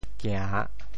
潮州府城POJ kiàⁿ 国际音标 [kĩã]